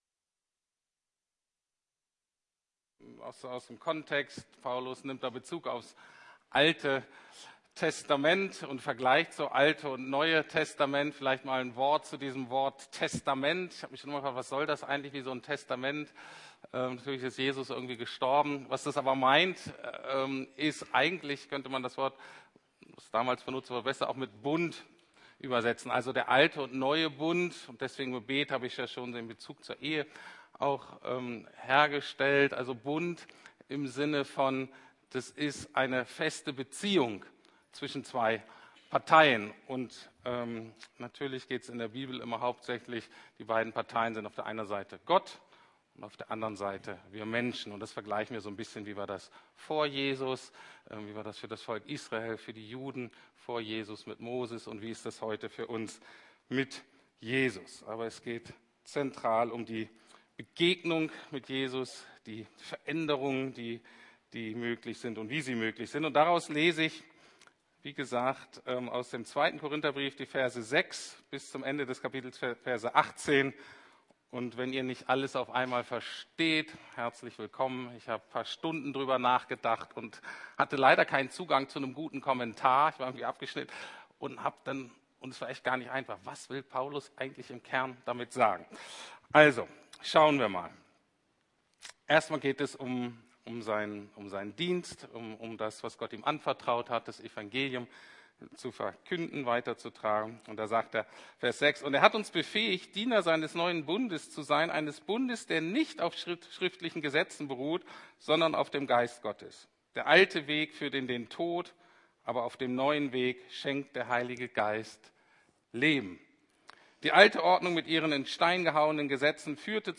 Paulus - Verwandelt durch Begegnung ~ Predigten der LUKAS GEMEINDE Podcast